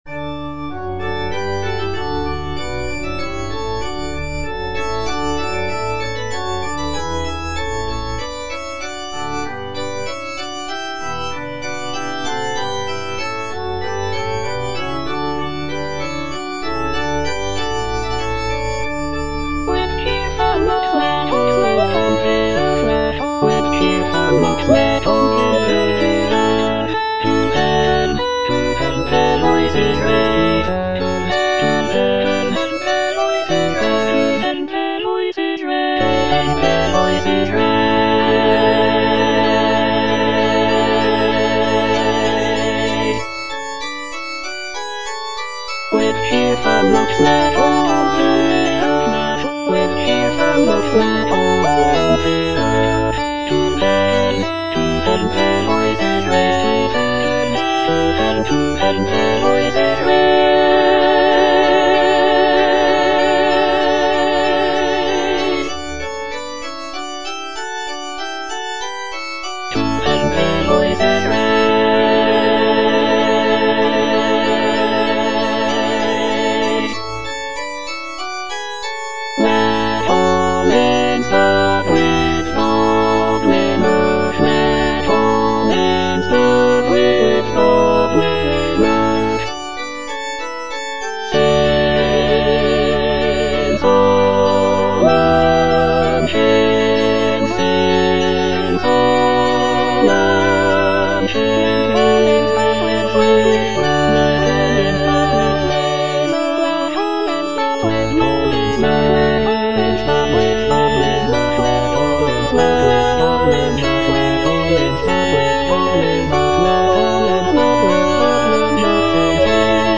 (A = 415 Hz) With cheerful notes (All voices) Ads stop
sacred choral work